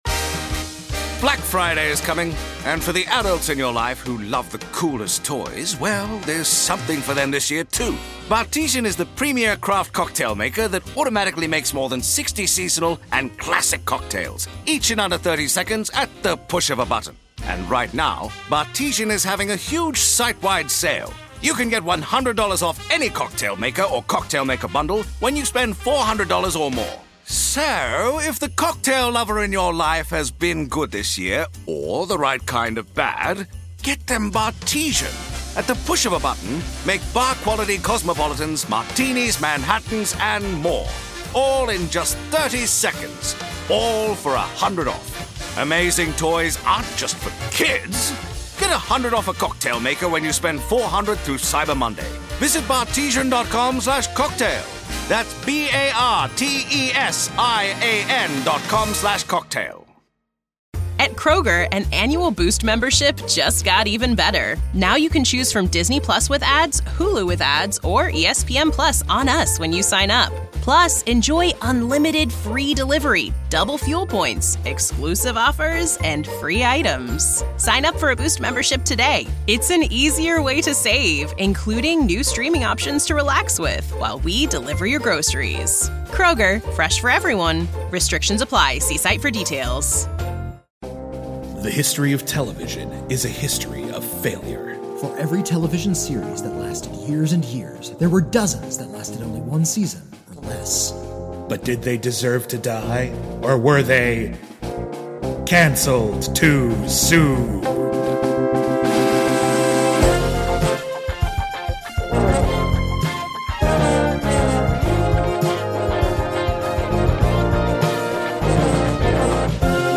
Doomsday Mixtape is a short actual play mini-series from Crit or Miss!